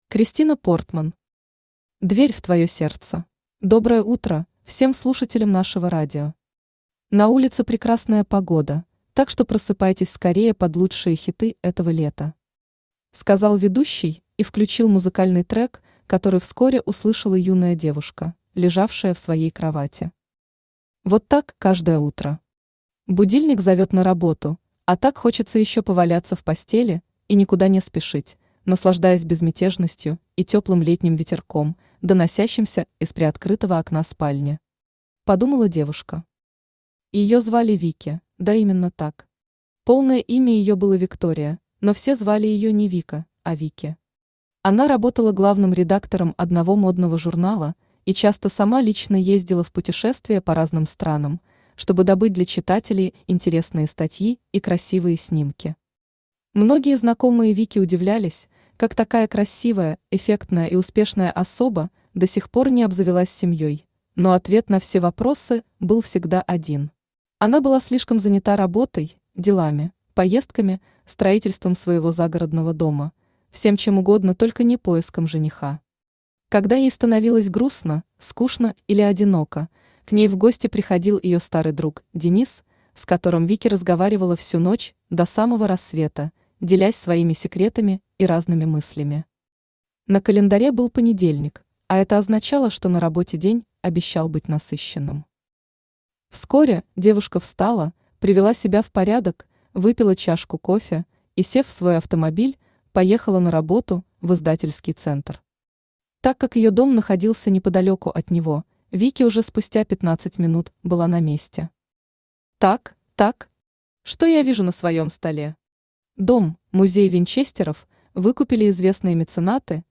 Аудиокнига Дверь в твое сердце | Библиотека аудиокниг
Aудиокнига Дверь в твое сердце Автор Kristina Portman Читает аудиокнигу Авточтец ЛитРес.